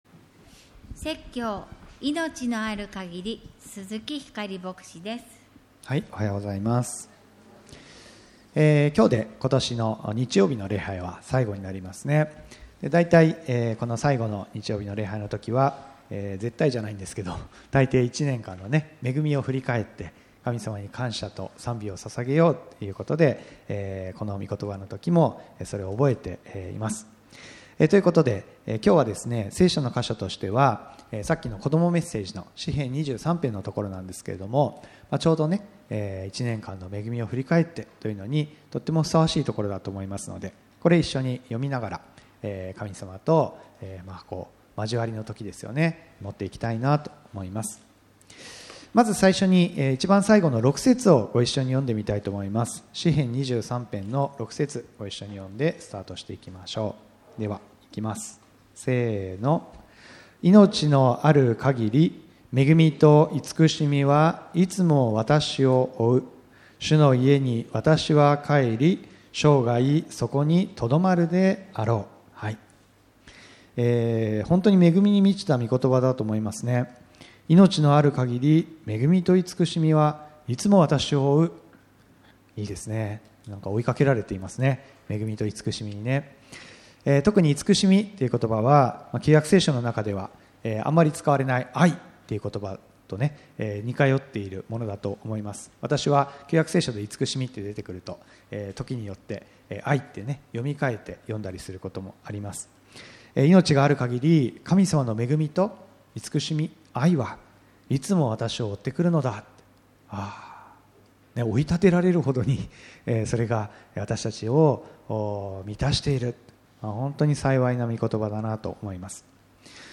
今年最後の日曜礼拝です。一年の恵みを振り返って祈りましょう。